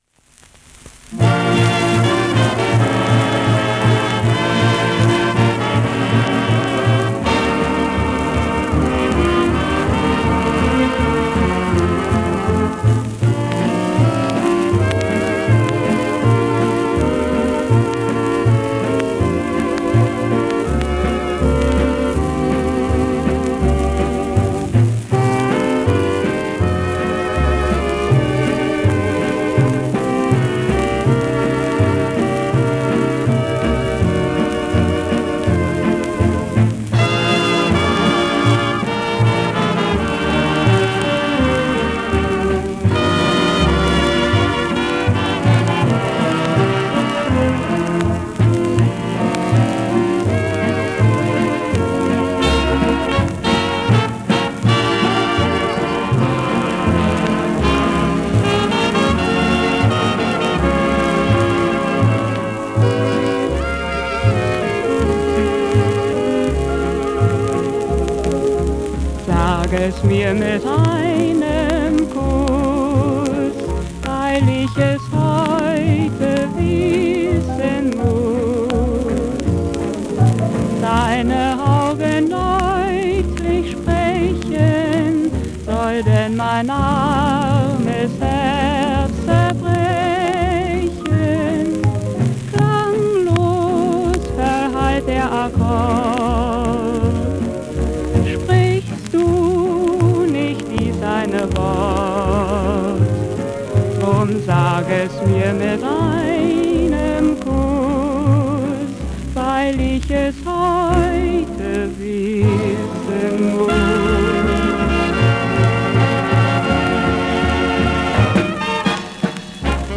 Heute knistern nicht nur die alten Platten, sondern auch die Leidenschaft, denn es geht mal wieder um das Küssen. Und zwar um Küsse aus den 1920er bis 1950er Jahren, die aber ganz und gar nicht staubig sind, die einen noch immer animieren und beim Hören entweder ein Lächeln auf die Lippen zaubern oder eine Träne aus dem Auge rollen lassen können.